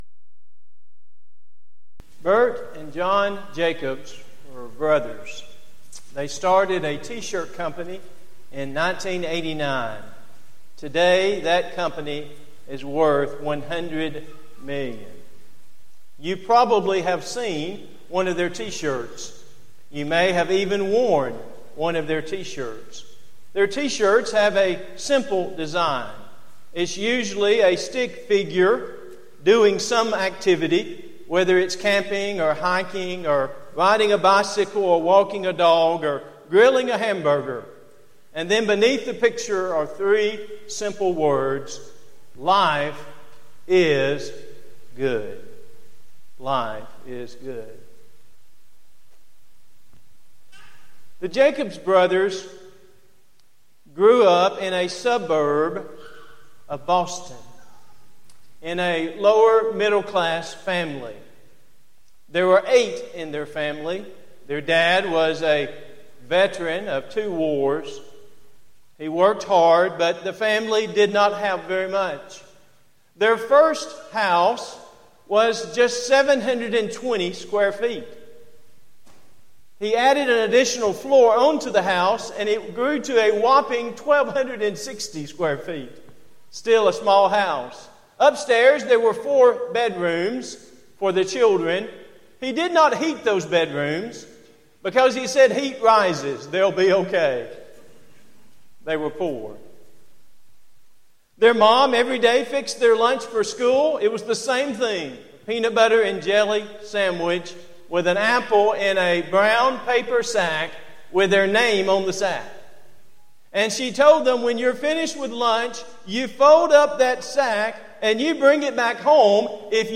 Event: 6th Annual Southwest Spiritual Growth Workshop Theme/Title: Arise and Overcome
lecture